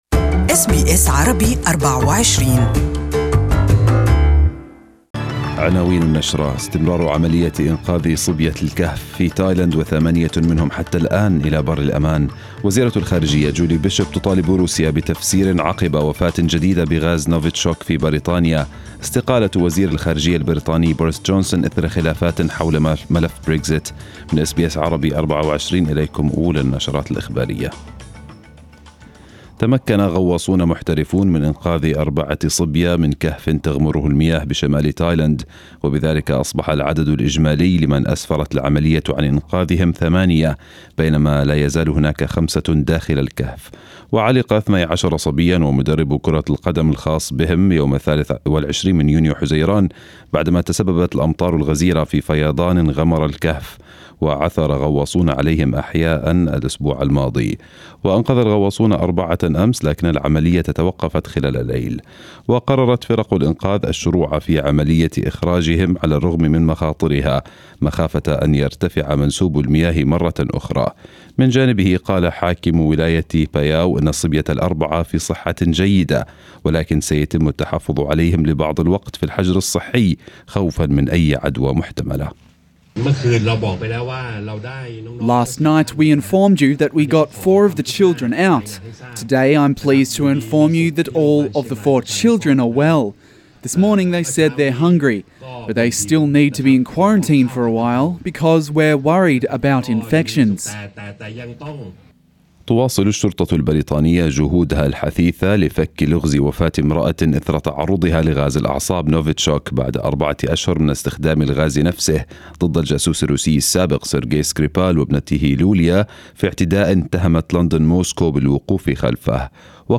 Arabic News Bulletin 10/07/2018